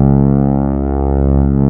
P.5 C#3.4.wav